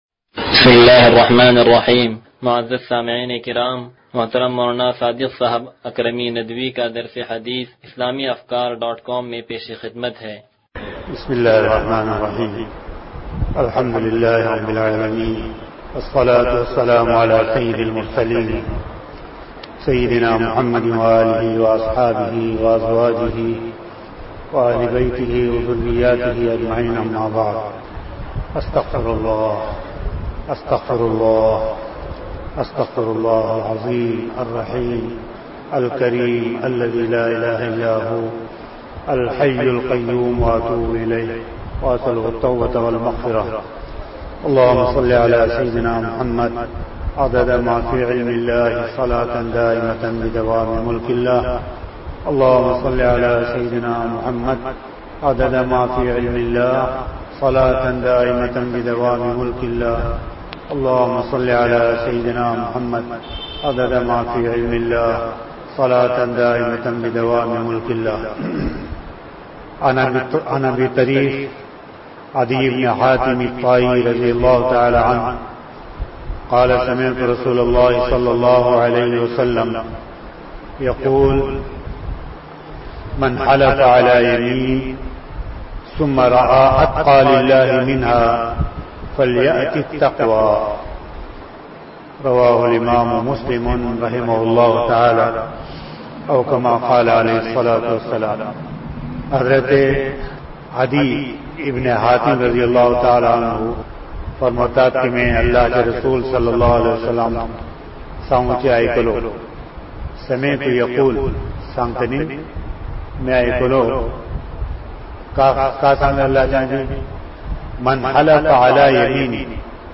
درس حدیث نمبر 0073